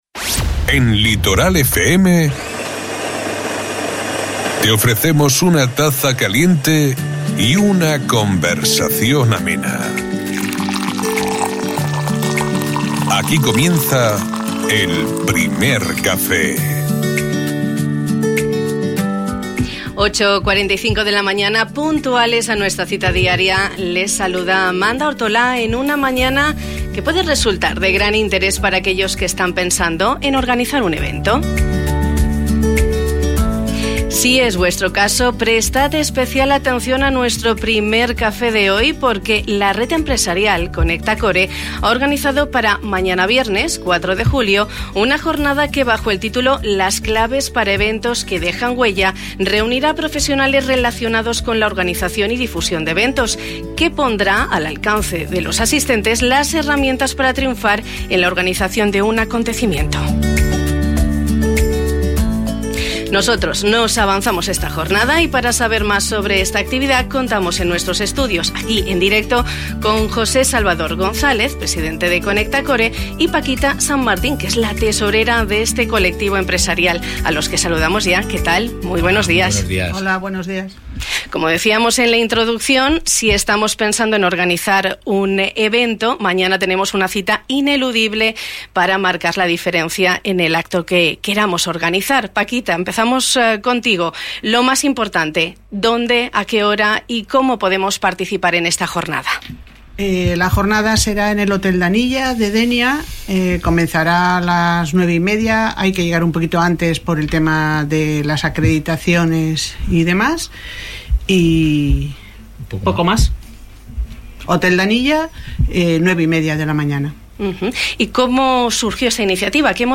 Esta mañana hemos compartido nuestro Primer Café con representantes de la red empresarial ConectaCore, que han preparado una convocatoria de gran interés para aquellos que están pensando en organizar un evento.